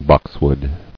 [box·wood]